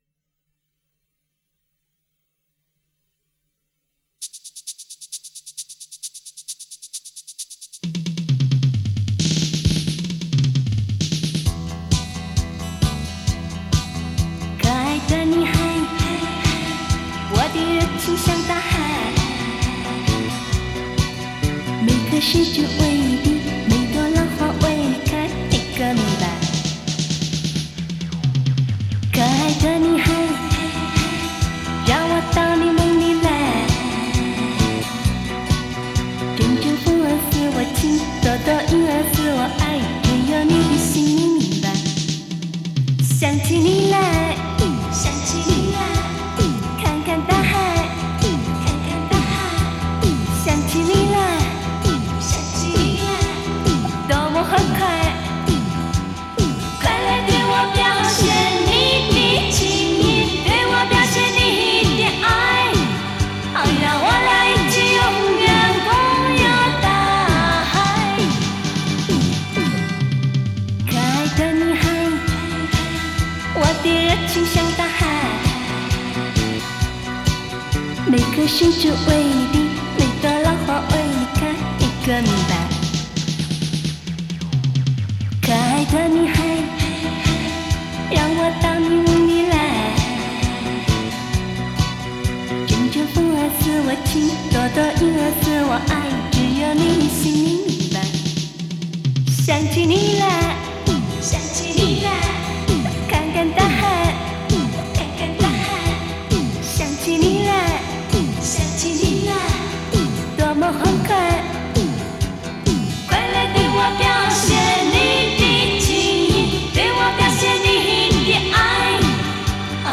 专辑介质：磁带